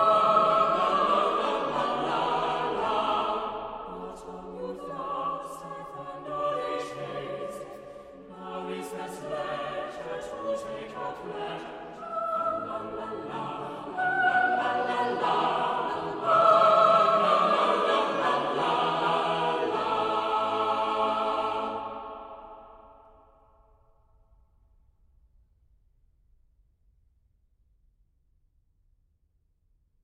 English romantic madrigals
"enPreferredTerm" => "Musique vocale profane"